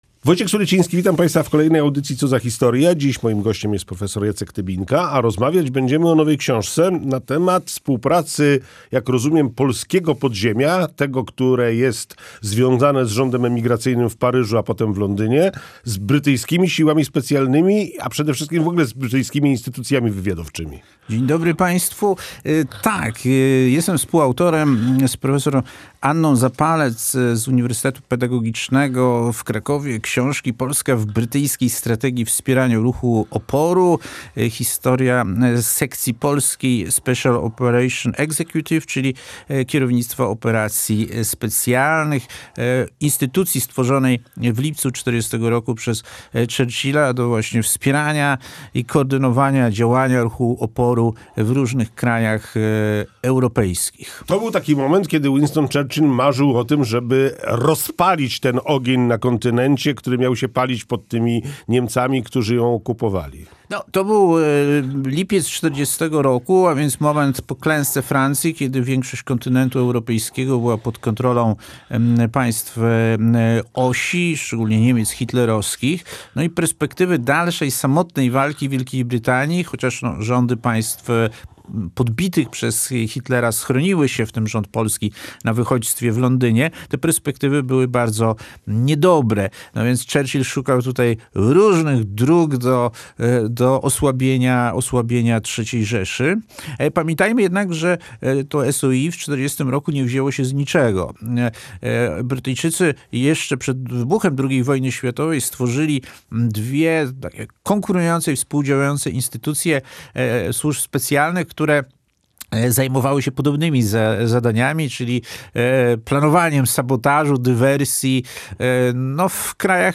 Winston Churchill marzył, żeby na kontynencie rozpalić ogień, który miał płonąć pod okupującymi Niemcami. W audycji rozmawiamy o współpracy polskiego podziemia, związanego z rządem emigracyjnym, z brytyjskimi siłami specjalnymi i instytucjami wywiadowczymi.